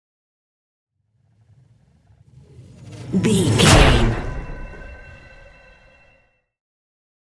Trailer dramatic whoosh to hit 450
Sound Effects
Atonal
intense
tension
woosh to hit